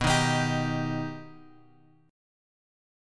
B+ chord {x 2 1 x 4 3} chord
B-Augmented-B-x,2,1,x,4,3.m4a